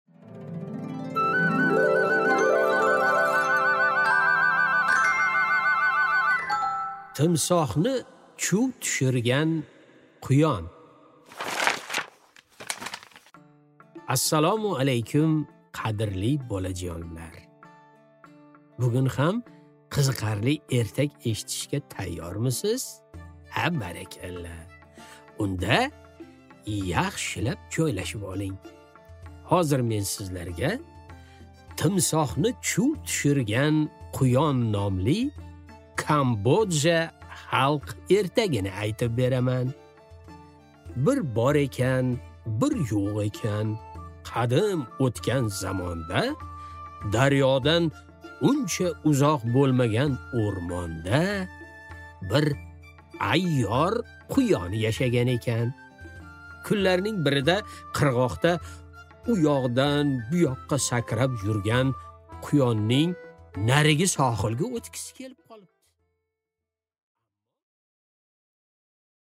Аудиокнига Timsohni chuv tushirgan quyon